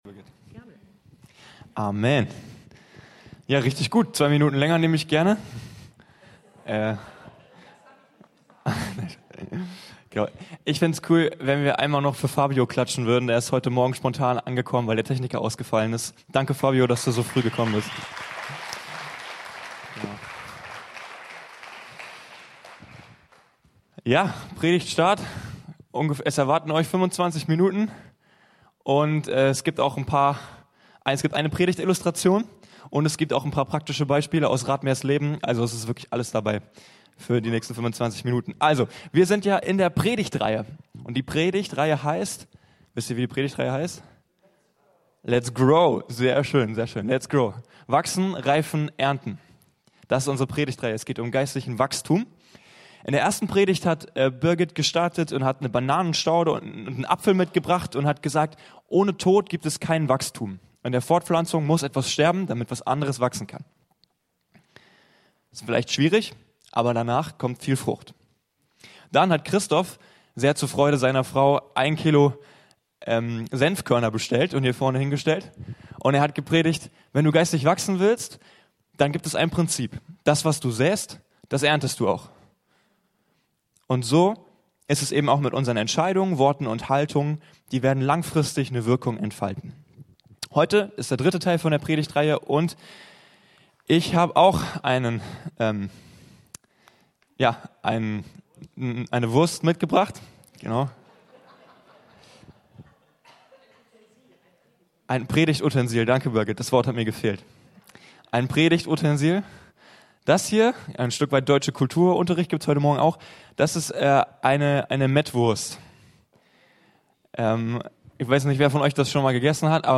Wachsen-Reifen-Ernten: Nachfolger machen Nachfolger, die Nachfolger machen (2Tim2,2) ~ Anskar-Kirche Hamburg- Predigten Podcast